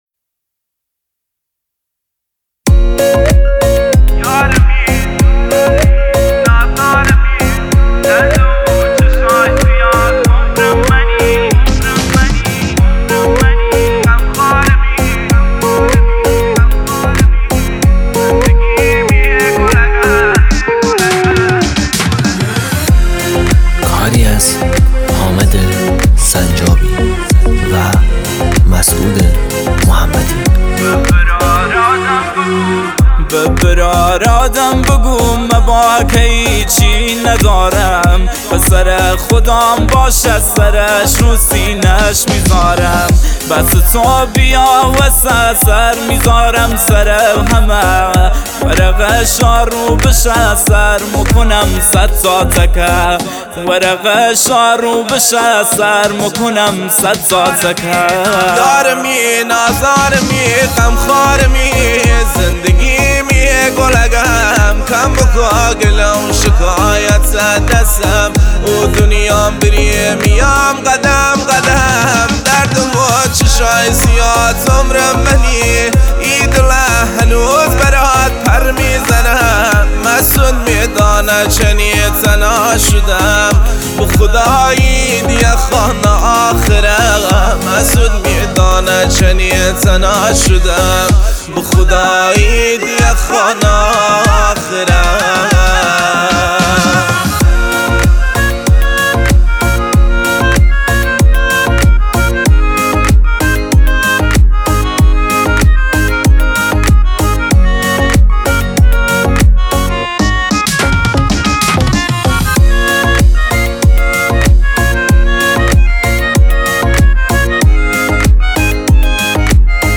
دسته : سنتی ایرانی